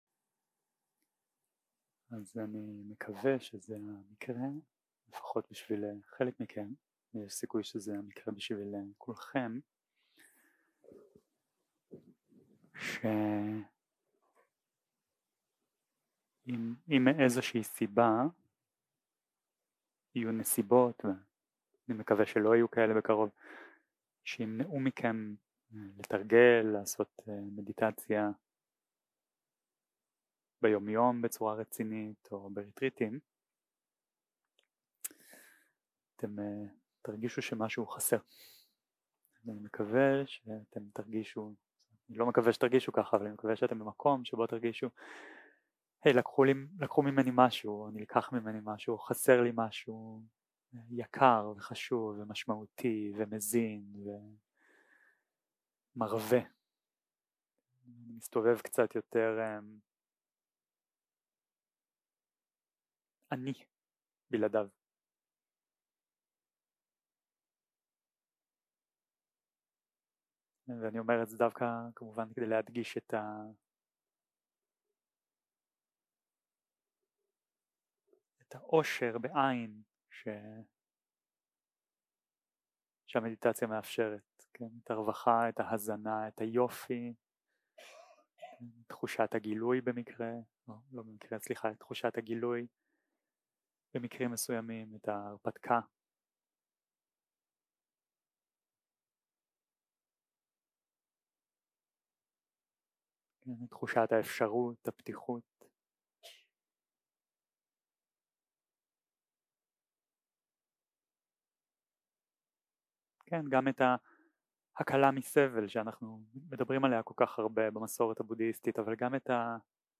יום 7 - הקלטה 10 - בוקר - הנחיות למדיטציה - מכשולים וג'האנה ראשונה 0:00 0:00 סוג ההקלטה: Dharma type: Guided meditation שפת ההקלטה: Dharma talk language: Hebrew